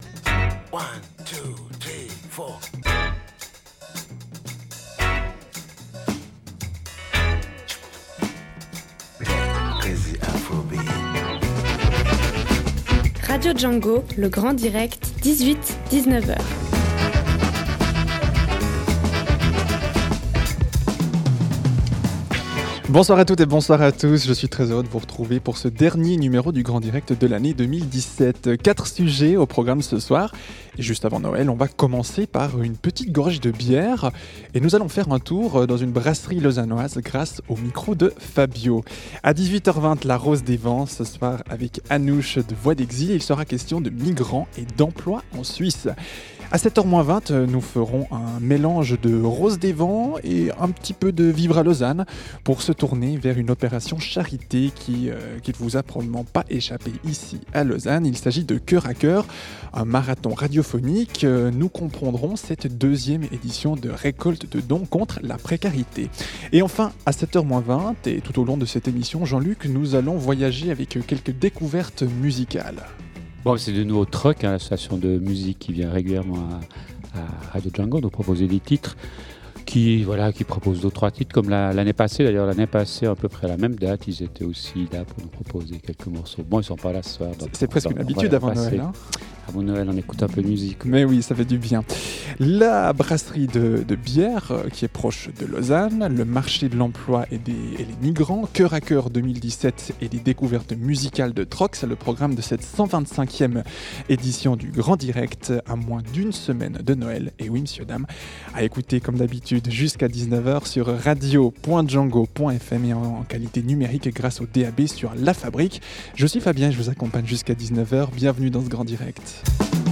• De la Bière avec un micro à la brasserie ®